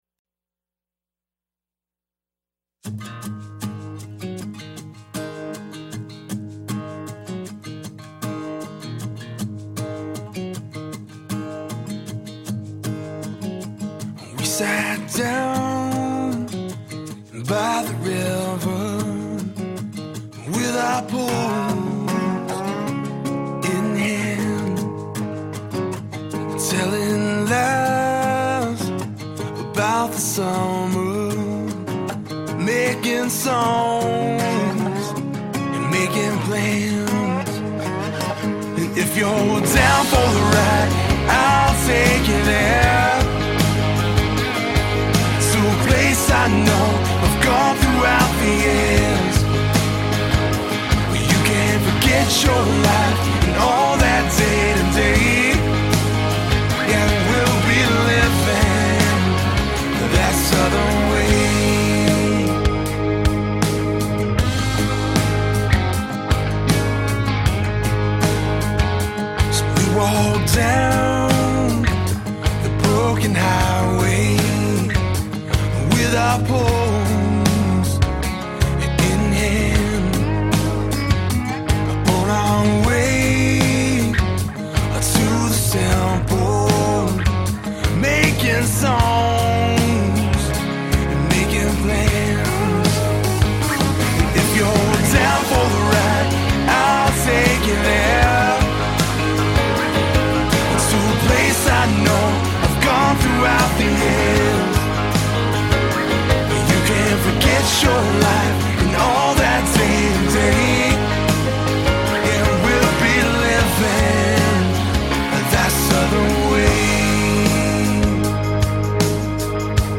a singer/songwriter